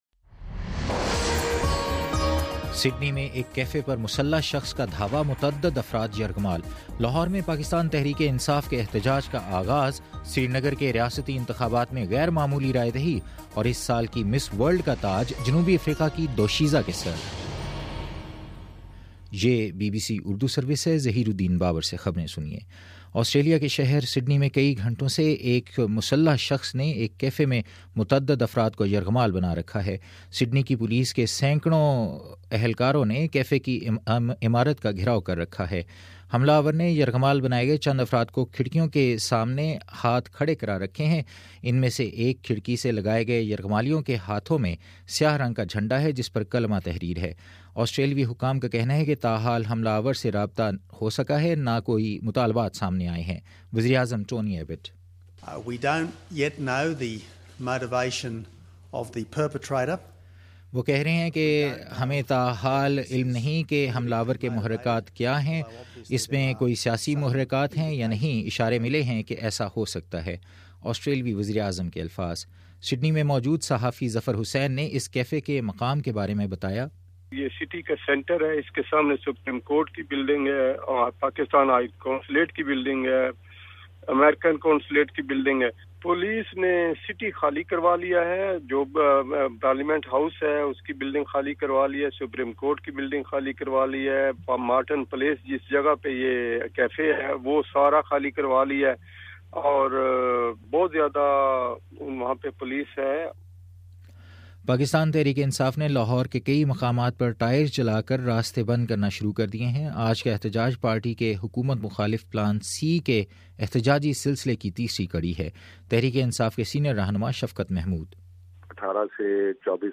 دسمبر15: صبح نو بجے کا نیوز بُلیٹن